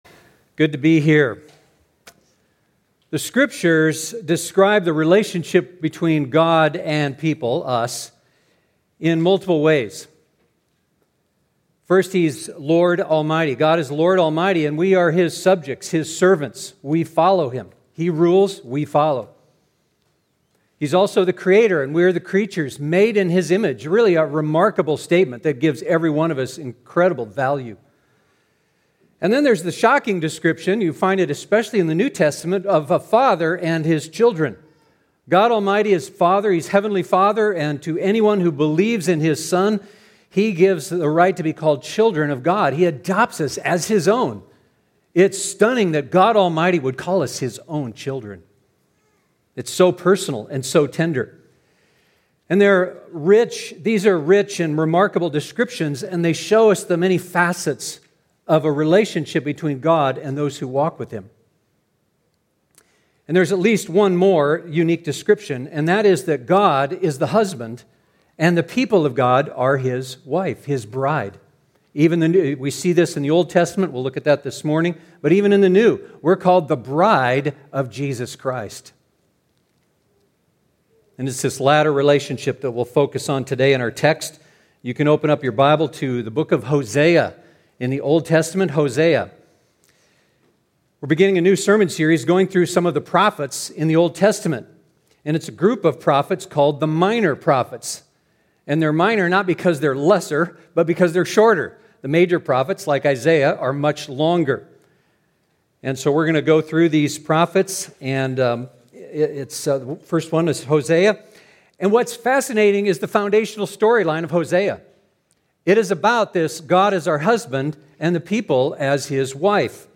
The Minor Prophets Hosea SERMON POWERPOINT Sunday, March 7, 2021 Minor Prophets Hosea and The Mercy of God The Scriptures describe the relationship between God and his followers in multiple ways.